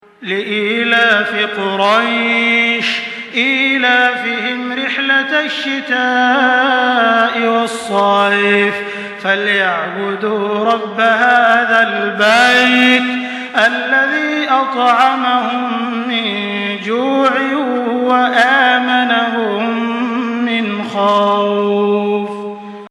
سورة قريش MP3 بصوت تراويح الحرم المكي 1424 برواية حفص
مرتل